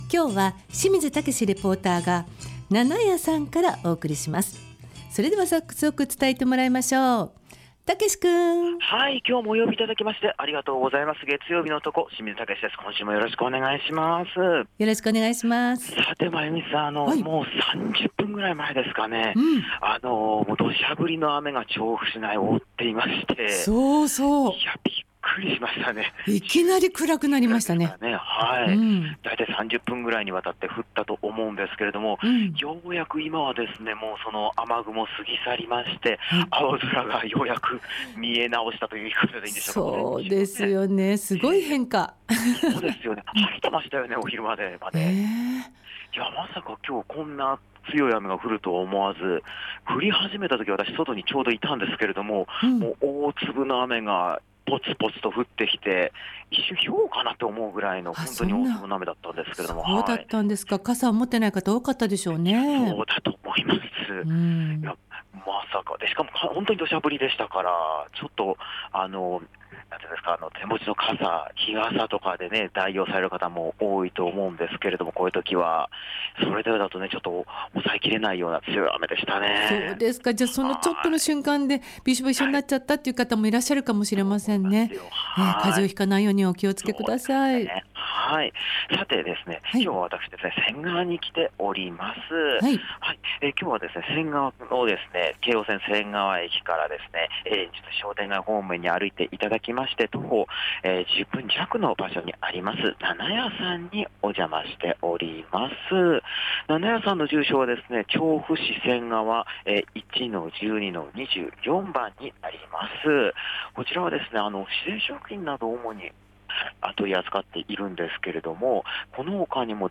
雨が降ったり止んだりというすっきりしない空の下からお届けした街角レポートは、 仙川でオーガニック製品を扱い続けて16年の「納々屋」さんにさんからのレポートでした！